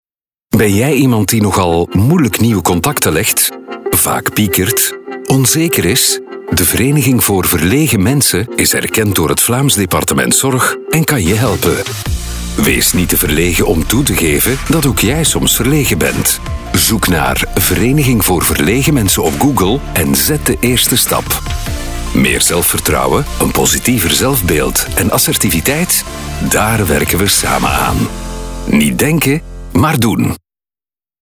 Radiospot VVM :
Radiospot-VVM-Productie-Bruo-BV-1.mp3